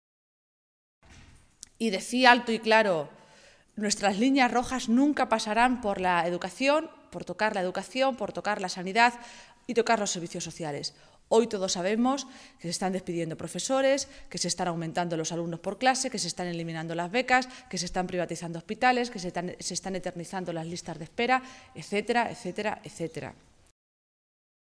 Cristina Maestre, portavoz de la Ejecutiva Regional del PSOE de Castilla-La Mancha
Cortes de audio de la rueda de prensa